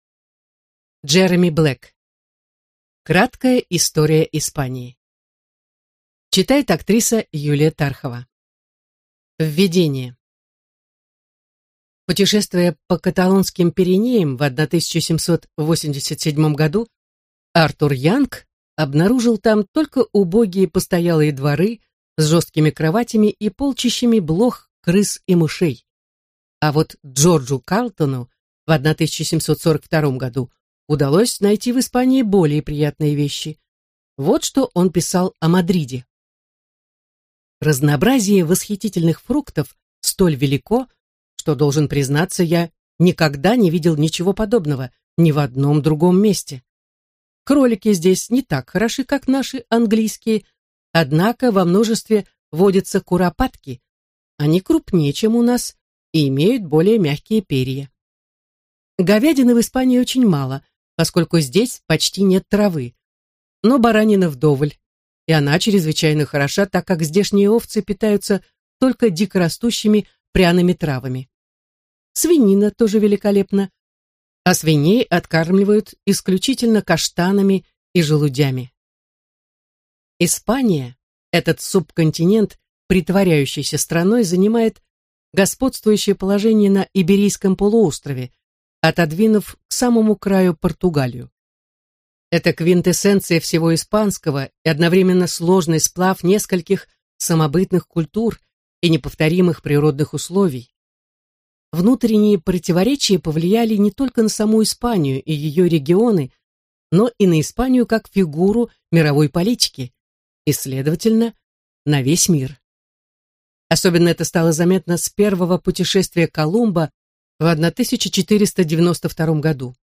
Аудиокнига Краткая история Испании | Библиотека аудиокниг